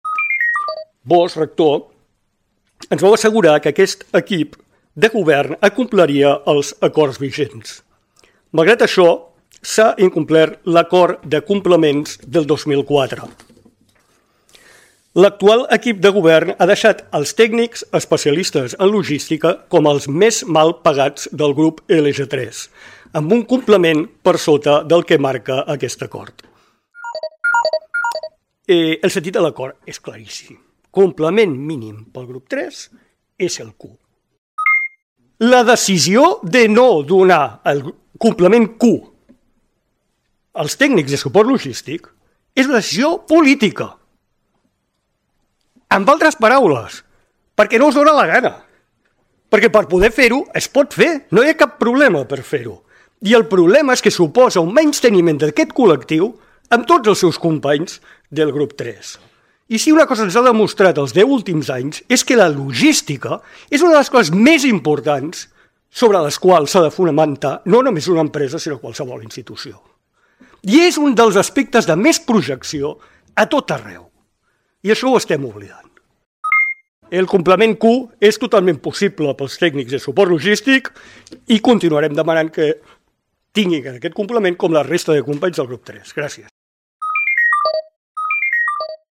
Intervenció dels CAU-IAC al Claustre de desembre de 2024 (fragment)